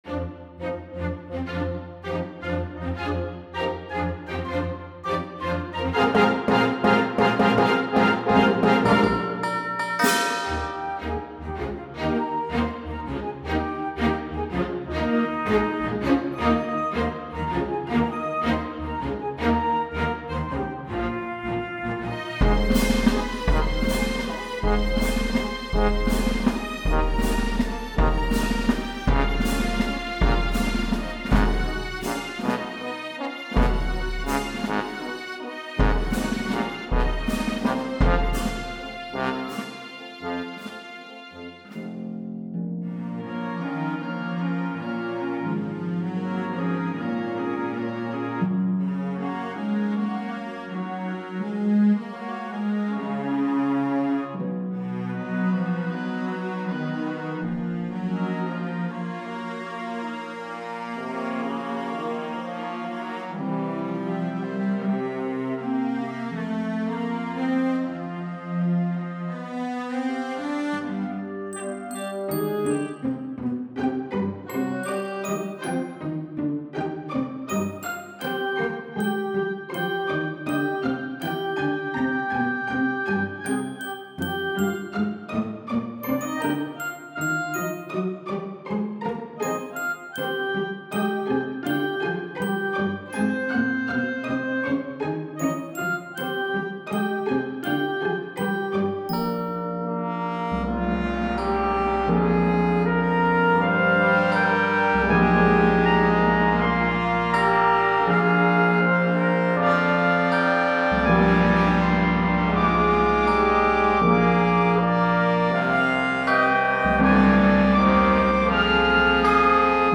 Can anyone hear the “Paint your Wagon” influence?